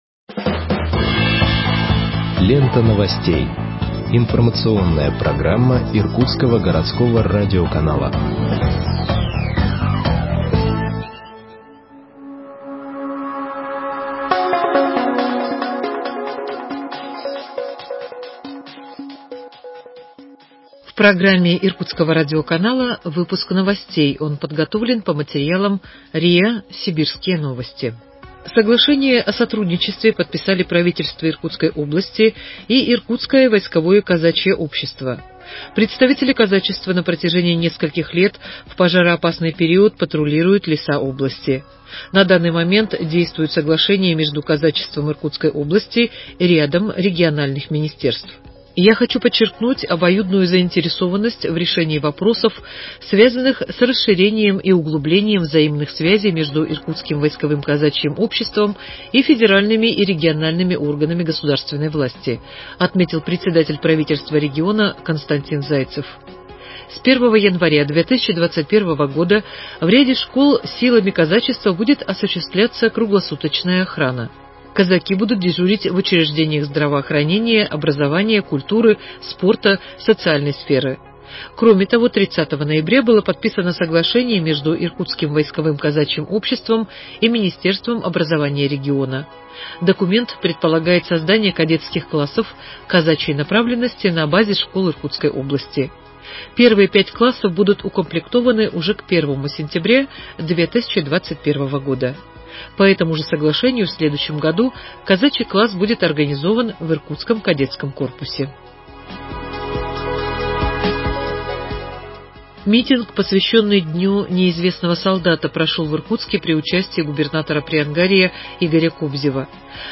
Выпуск новостей в подкастах газеты Иркутск от 07.12.2020 № 1